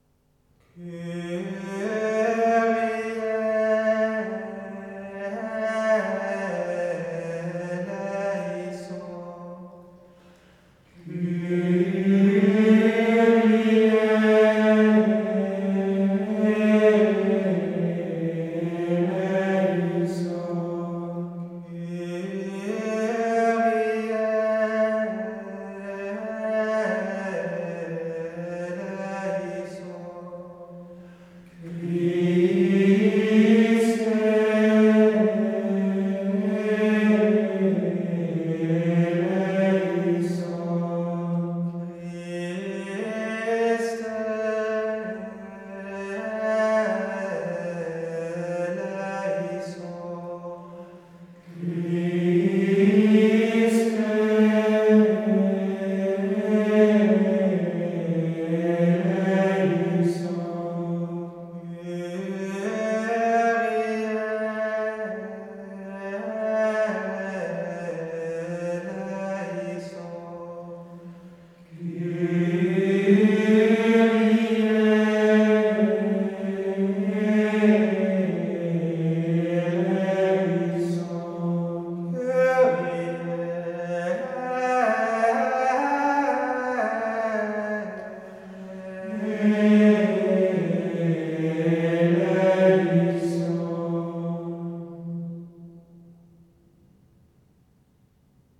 Missa pro defunctis Kyrie (Musik)